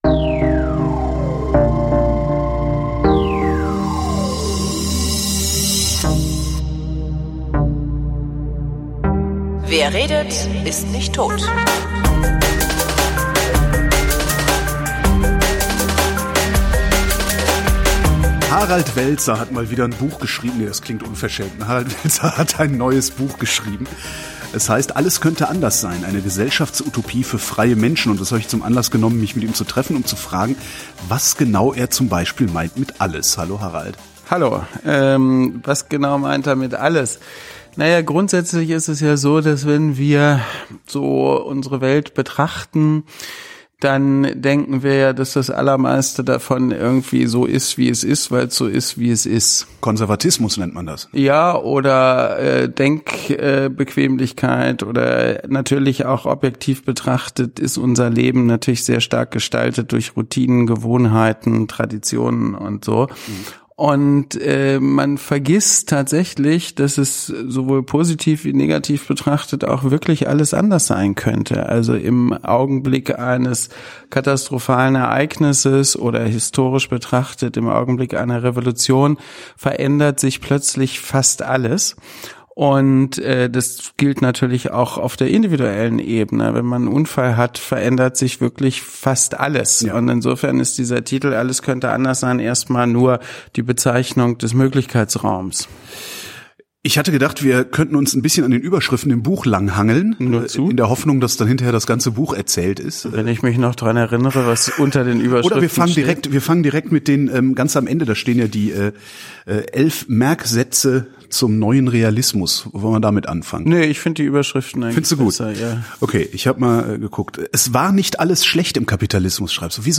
wrint: gespräche zum runterladen
Harald Welzer ist Soziologe, Sozialpsychologe und Direktor der Stiftung futurzwei. Sein letztes Buch heißt “Alles könnte anders sein“* und ich habe ihn getroffen, um zu fragen, was er damit meint.